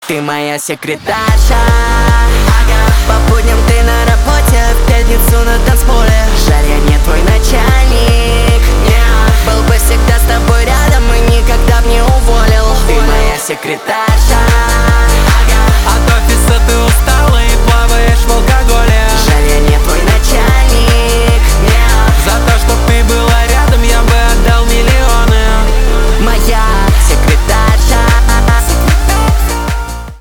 поп
битовые
качающие